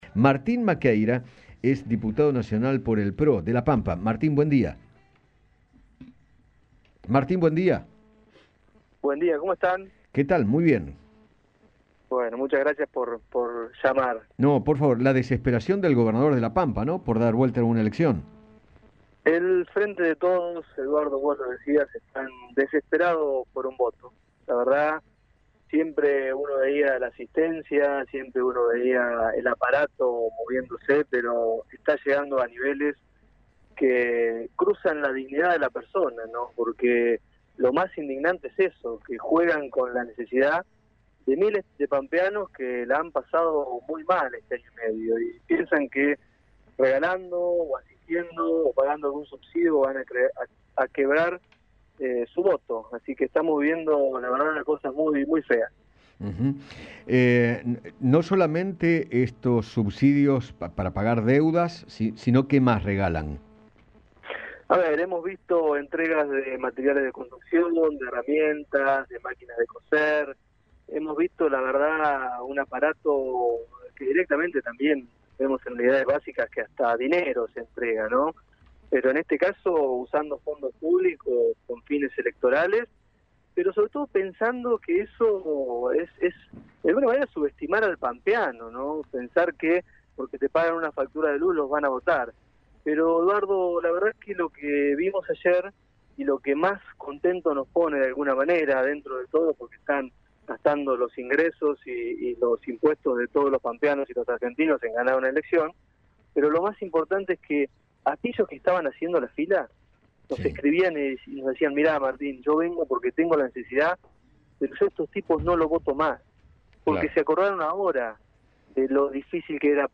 Martín Maquieyra, diputado nacional, dialogó con Eduardo Feinmann sobre la nueva medida del actual Gobierno pampeano y manifestó que “están desesperados, piensan que pagándoles una boleta de luz los van a votar”.